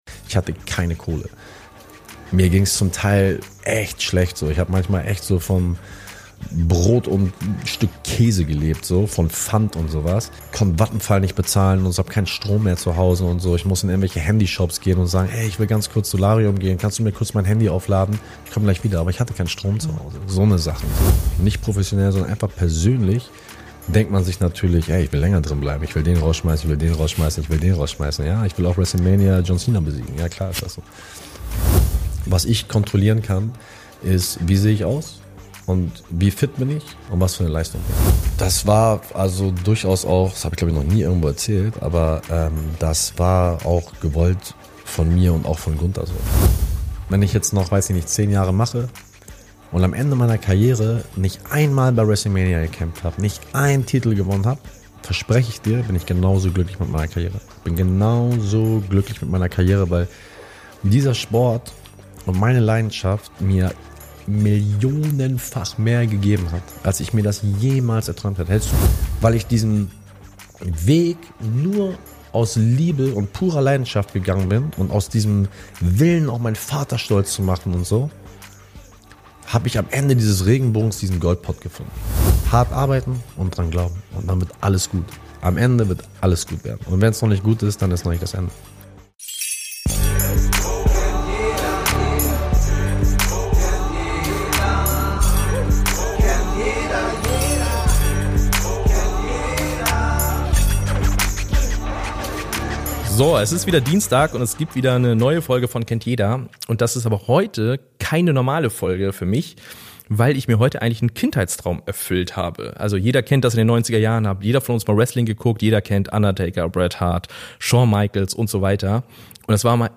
Ein ehrliches und spannendes Gespräch mit einem echten Superstar, dass du so garantiert noch nicht gehört hast.